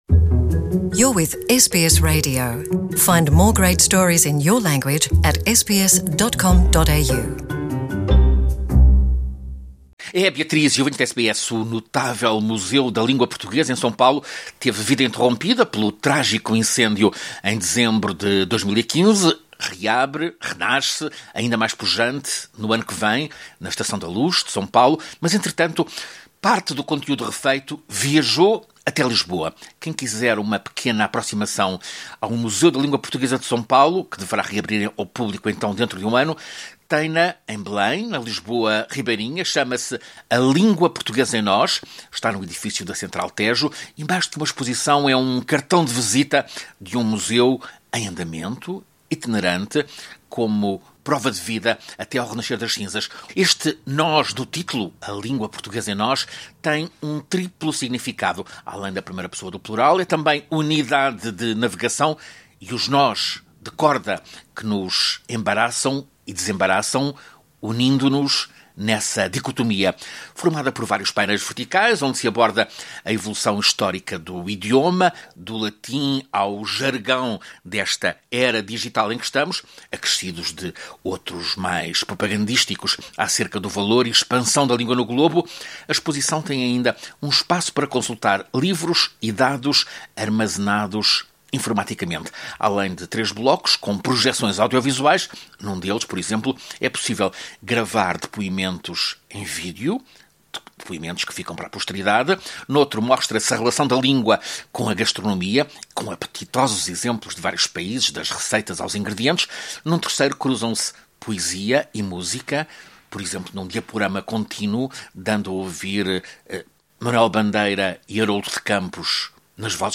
Ouça reportagem do correspondente da SBS em Português